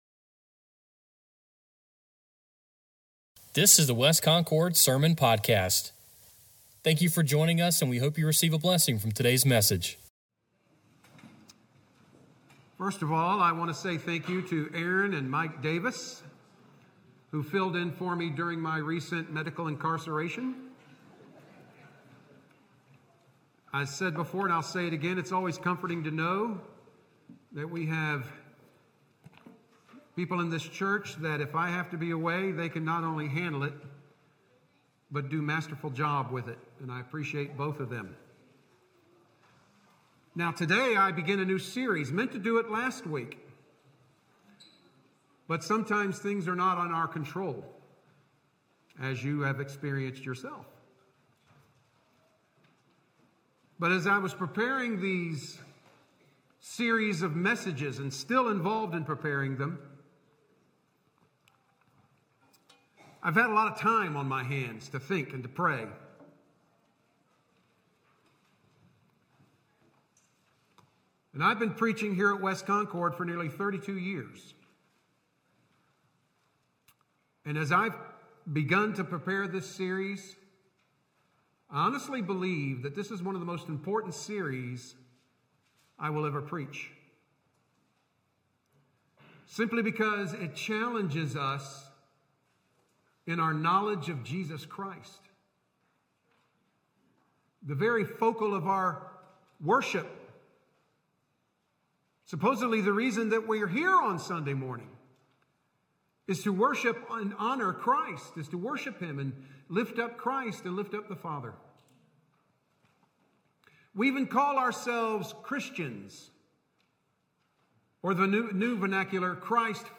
Sermon Podcast | West Concord Baptist Church